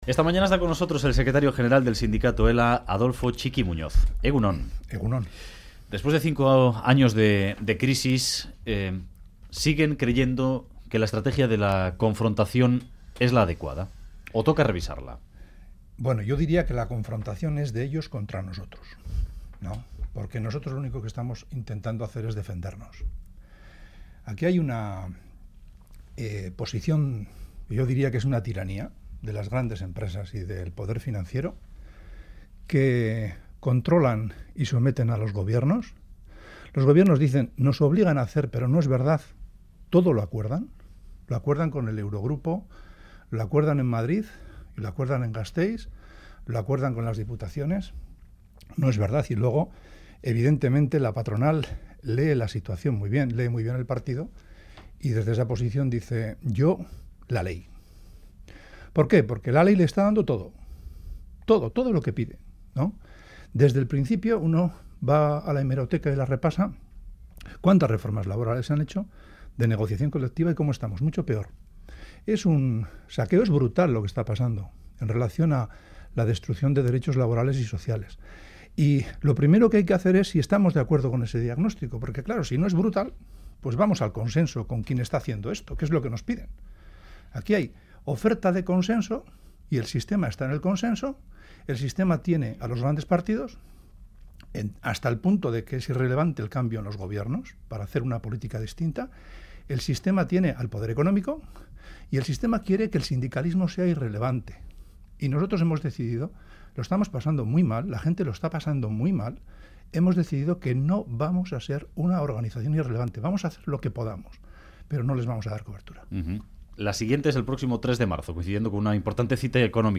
En entrevista a Radio Euskadi